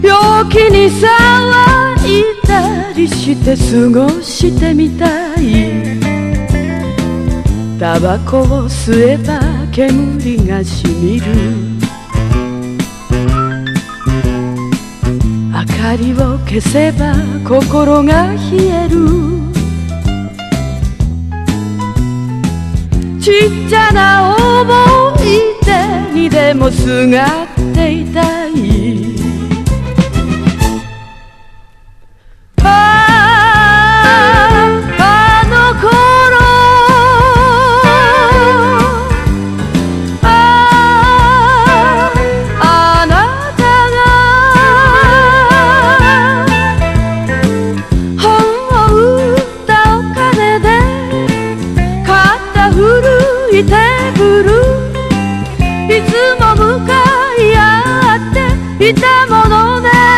CHILDREN (JPN)